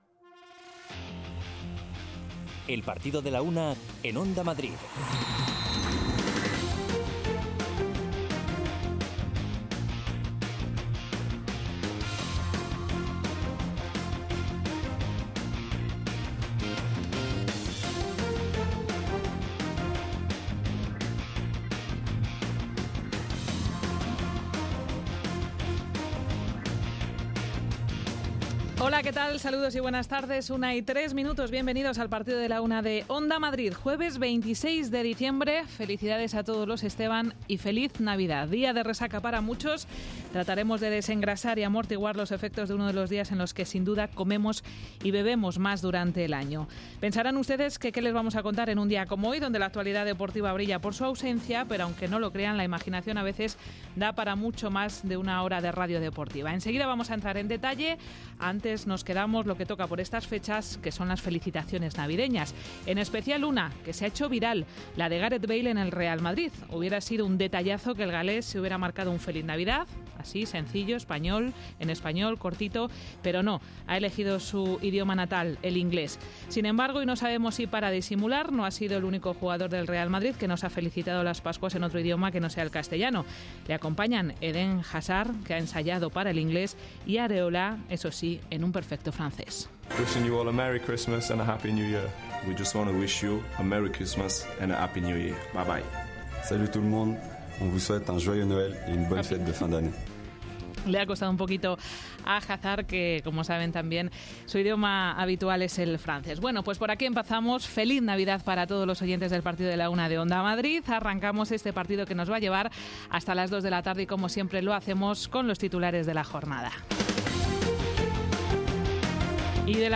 Es la referencia diaria de la actualidad deportiva local, regional, nacional e internacional. El rigor en la información y el análisis medido de los contenidos, con entrevistas, reportajes, conexiones en directo y el repaso a la agenda polideportiva de cada día, son la esencia de este programa.